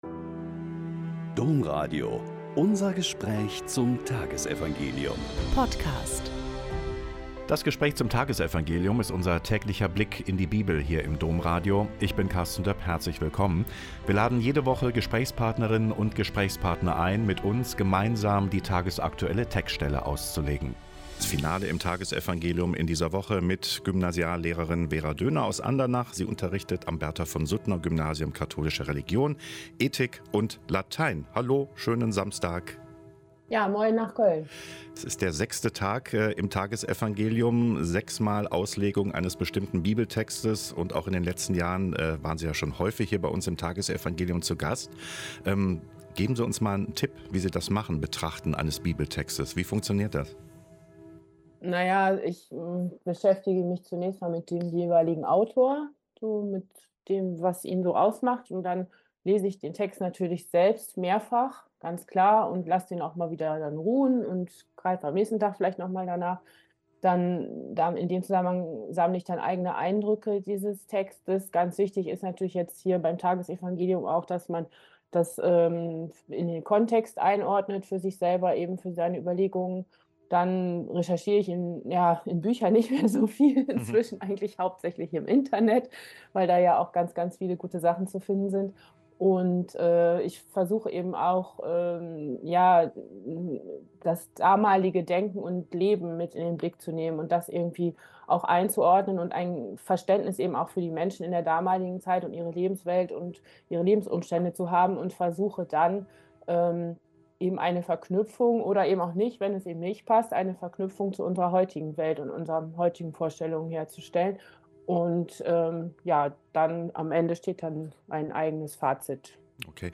Mk 2, 13-17 - Gespräch